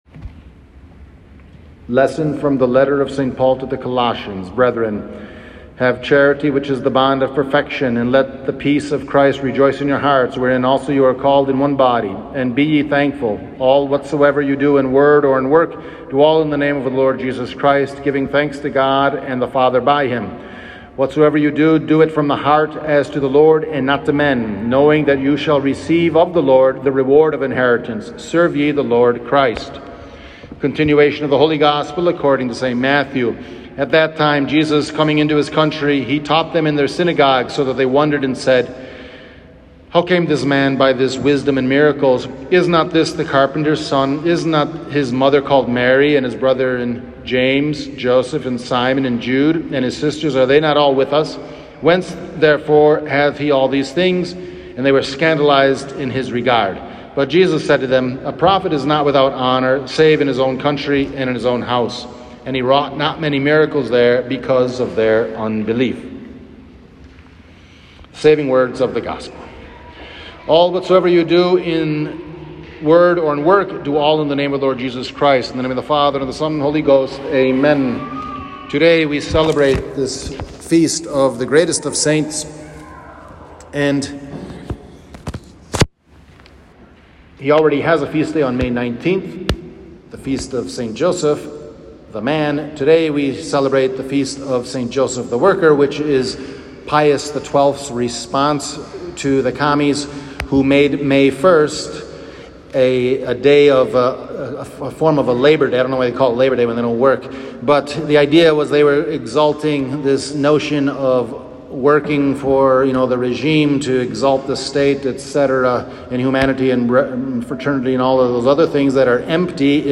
St. Joseph the Worker — Homily